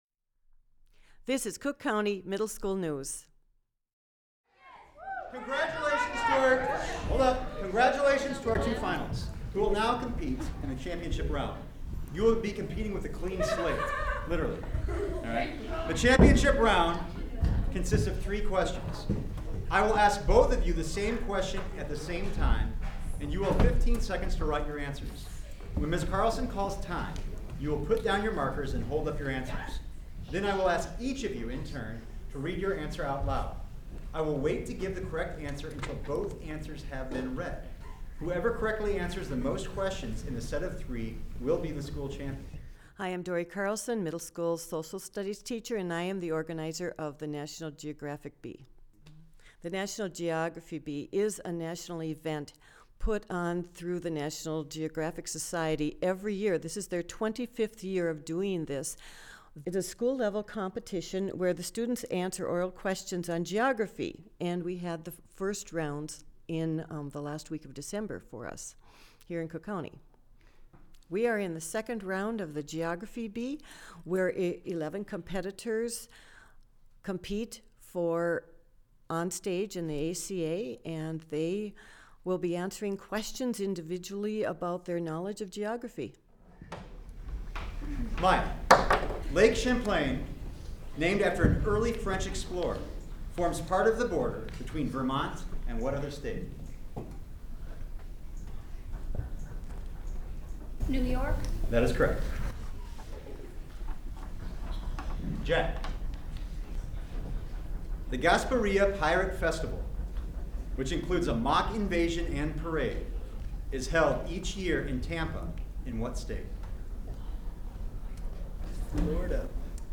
School News